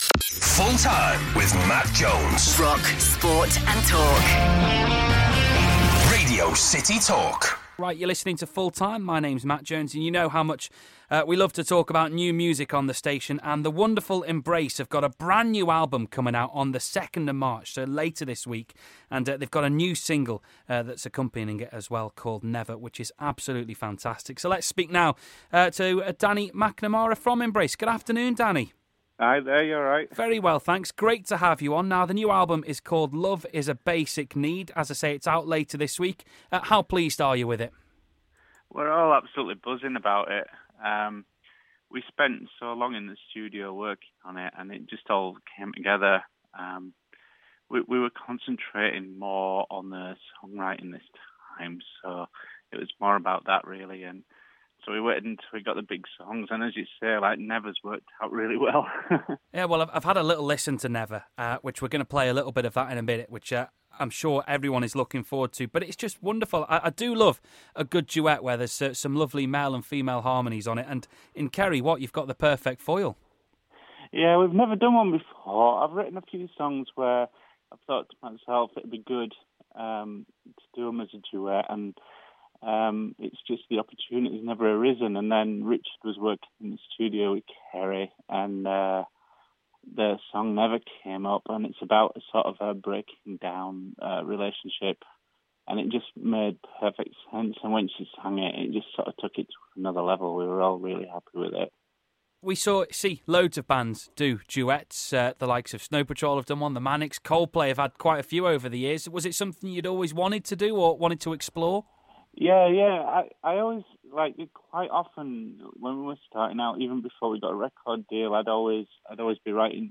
Embrace's lead singer Danny McNamara